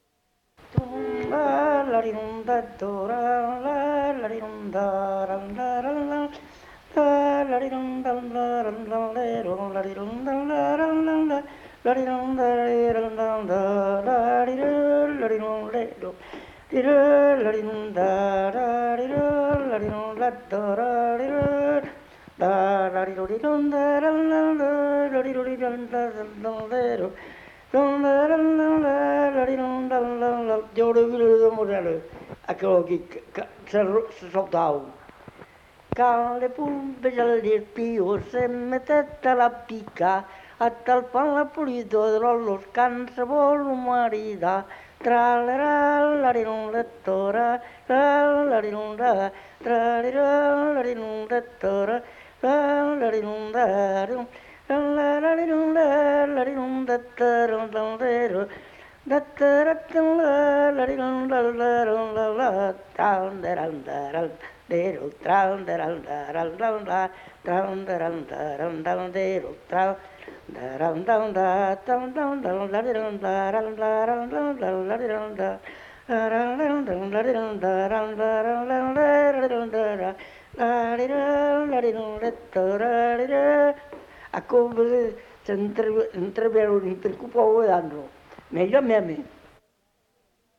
Aire culturelle : Cabardès
Genre : chant
Effectif : 1
Type de voix : voix d'homme
Production du son : chanté ; fredonné
Danse : polka piquée
Notes consultables : Fredonne l'air en imitant le son de la bodega avant de chanter le couplet à danser.